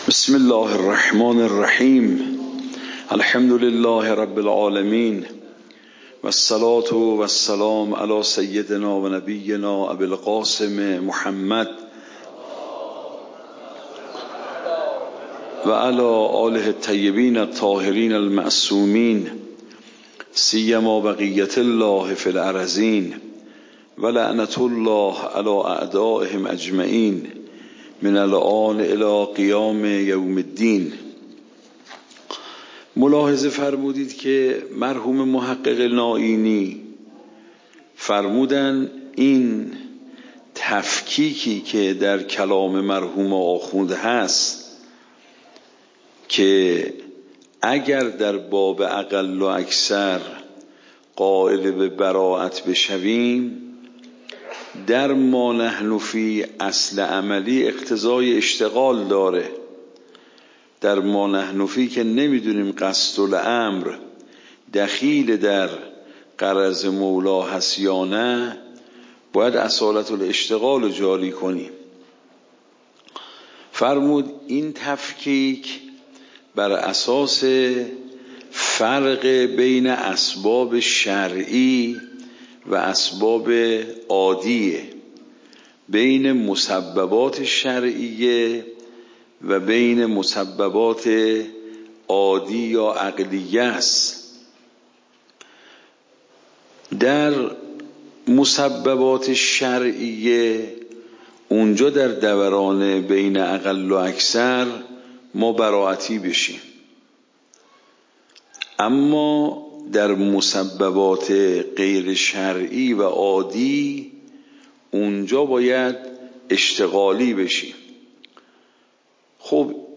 درس بعد تعبدی و توصلی درس قبل تعبدی و توصلی درس بعد درس قبل موضوع: واجب تعبدی و توصلی اصول فقه خارج اصول (دوره دوم) اوامر واجب تعبدی و توصلی تاریخ جلسه : ۱۴۰۴/۲/۶ شماره جلسه : ۸۲ PDF درس صوت درس ۰ ۳۱۷